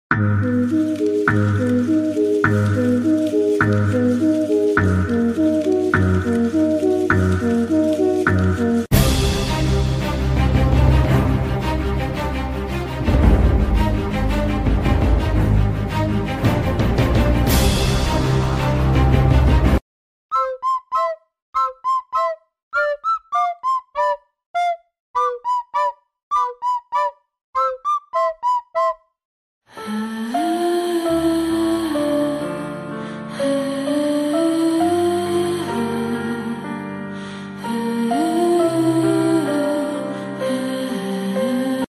影视解说BGM通常具有强烈的情感色彩，能引导观众情绪，使其对解说内容产生共鸣。